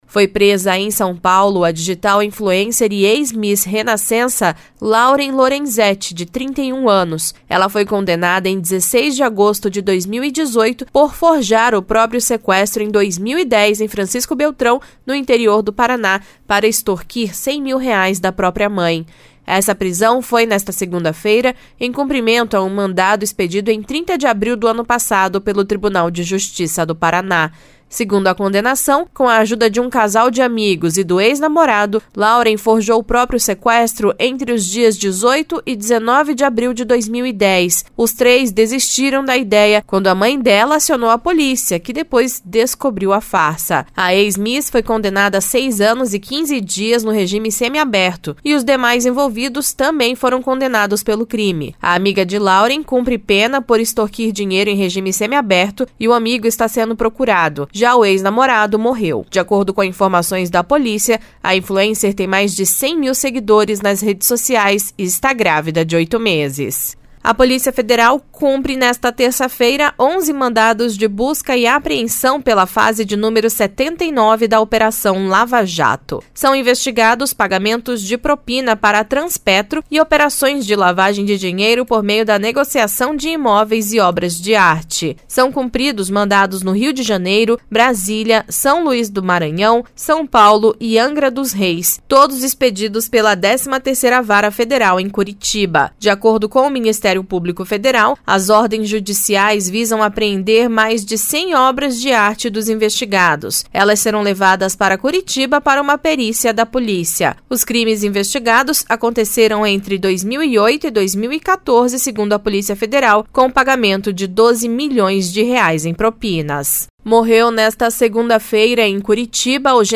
Giro de Notícias Manhã SEM TRILHA